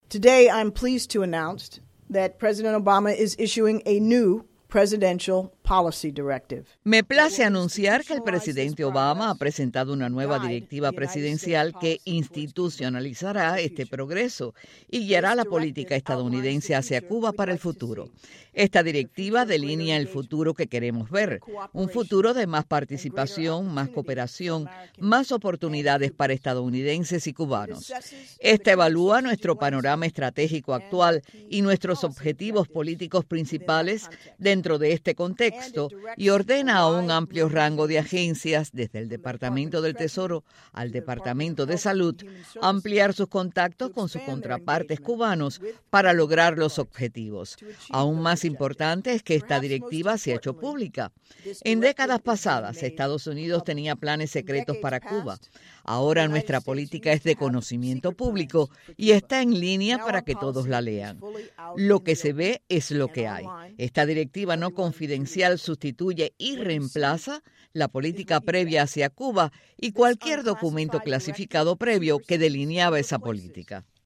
La principal asesora de seguridad nacional de Obama, Susan Rice, habló en una conferencia en el Wilson Center sobre la más reciente directiva presidencial para Cuba.
Declaración de Susan Rice sobre la más reciente directiva presidencial para Cuba: